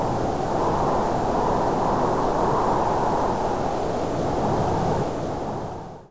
This is the file that plays whenever the wind changes direction (on the hour if so set up that way, which yours likely is)....I downloaded a free sound manipulation program and did it in about five minutes! All I did was copied the last second of the sound, pasted it onto the end of the file, and added a fade out (which was conveniently one of the pre made options in the program) Enjoy....my hunch is it will be included in a mod pack near you one day soon
wind_sea4-wav.8089